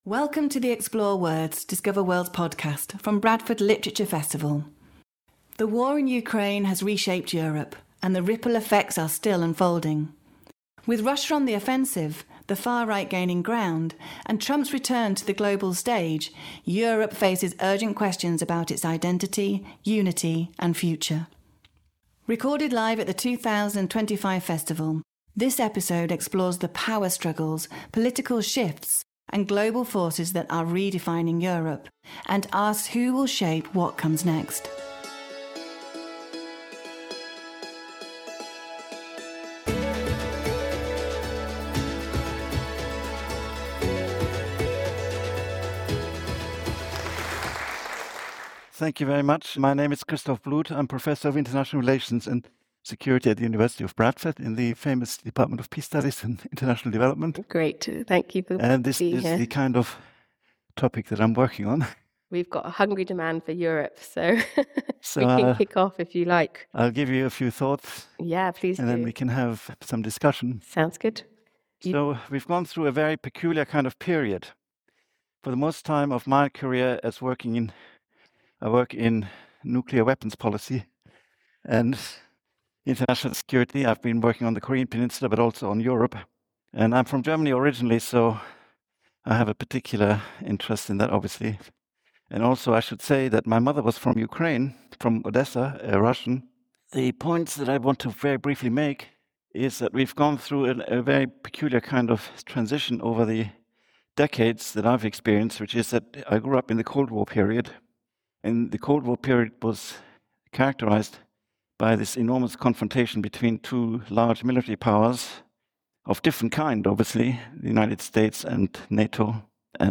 This timely event explores the power struggles, political shifts, and global forces that are redefining Europe — and asks who will shape what comes next.